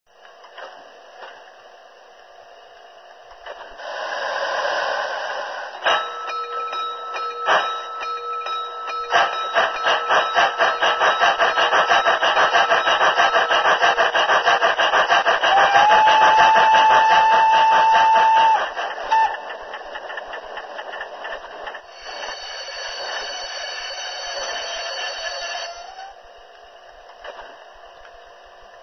Esta es una grabación de parte de los sonidos incorporados en el Intellisound 4 correspondiente a una locomotora diesel media. Los sonidos se reproducen conjuntamente, pero al utilizar el Intellisound puede elegirse en cada momento cuales queremos reproducir.